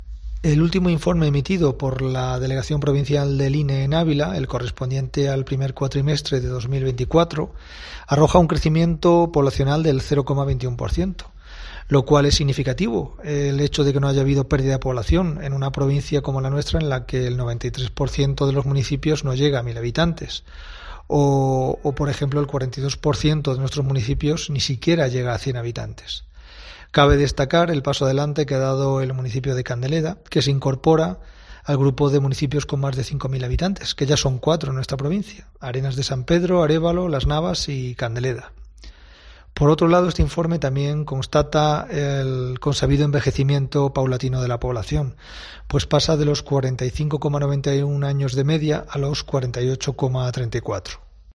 Audio / subdelegado del Gobierno en Ávila, Fernando Galeano
Eso sí, la edad media de la provincia se sitúa en los 48,34 años, cuando el año pasado era de 45,91 años... lo que confirman la tendencia al envejecimiento poblacional en Ávila, como ha explicado el subdelegado del Gobierno en Ávila, Fernando Galeano.